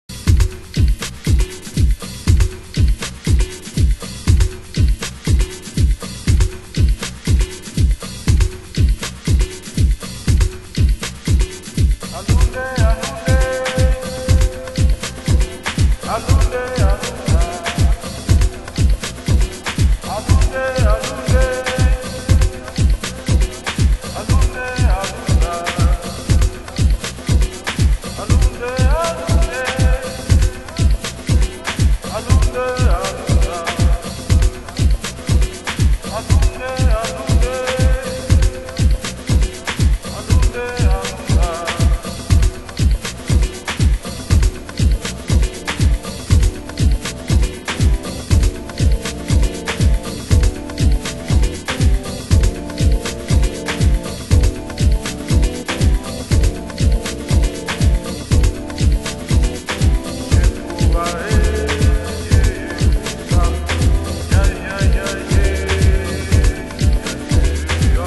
重厚なビート、打楽器系のKEYを配したアフロスペーシートラック！